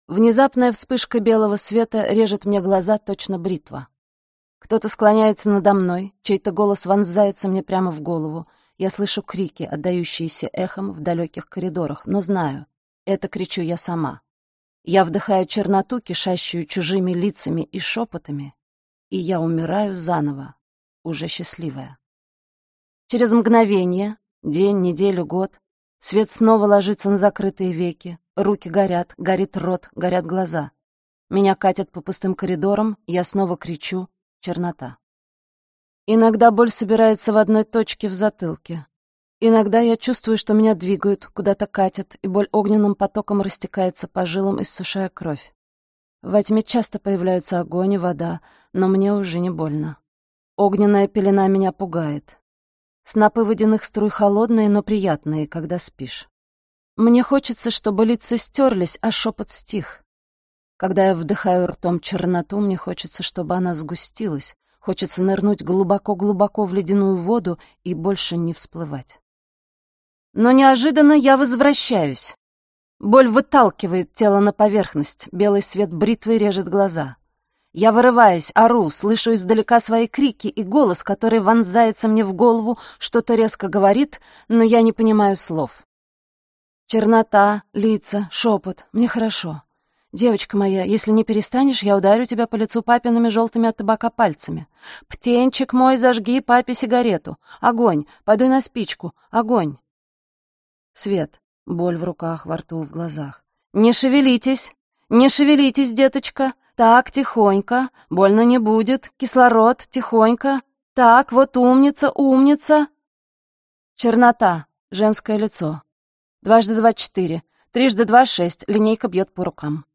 Аудиокнига Ловушка для Золушки | Библиотека аудиокниг
Aудиокнига Ловушка для Золушки Автор Себастьян Жапризо Читает аудиокнигу Ксения Кутепова.